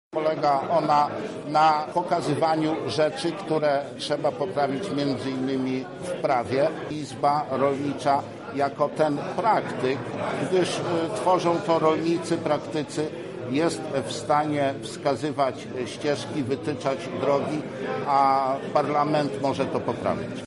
Na ten temat rozmawiali uczestnicy Wojewódzkiej Konferencji Rolnej i Obszarów Wiejskich.
O roli izb rolniczych mówi wiceminister Rolnictwa i Rozwoju Wsi Ryszard Zarudzki.